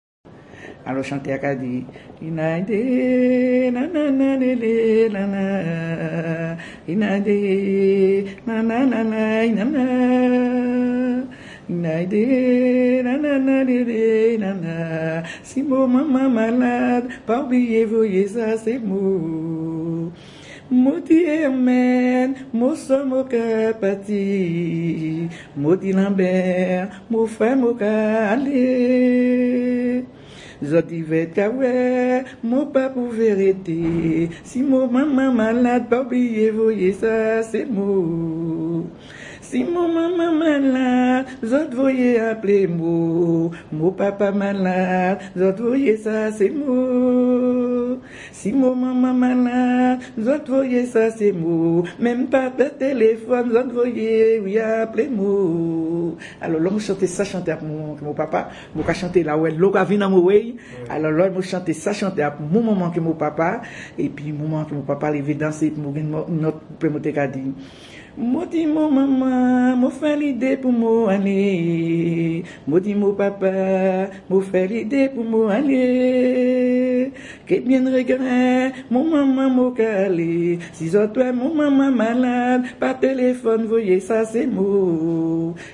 Chanson créée par l'interprète
Pièce musicale inédite